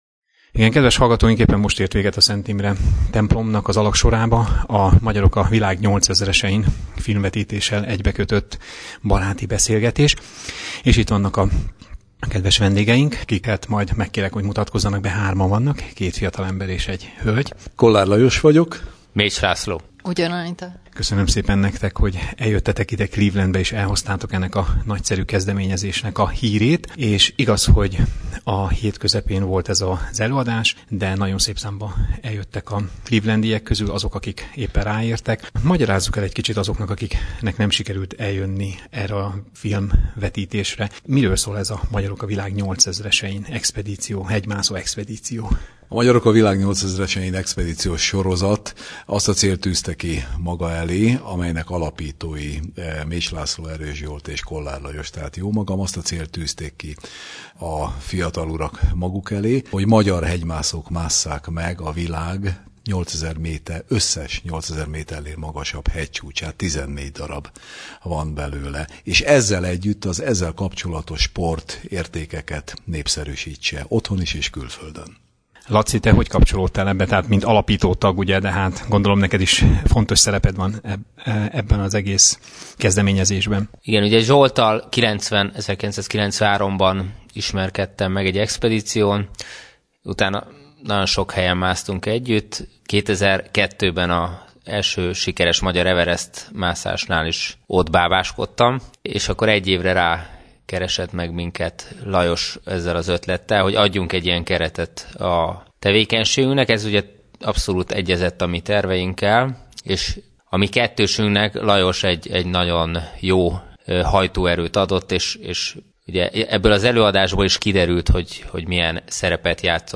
Az előadás után egy rövid interjút készítettem mindhármukkal egyszerre: